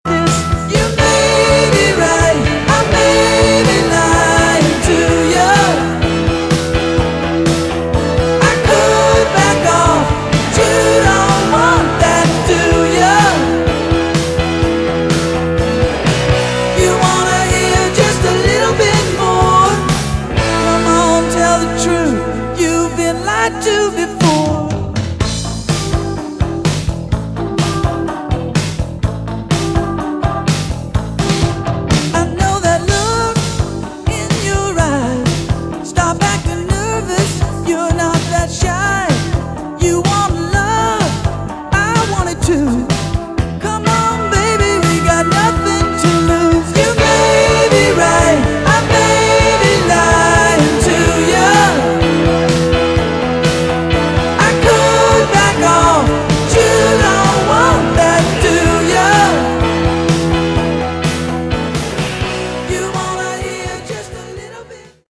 Category: AOR
Original Demo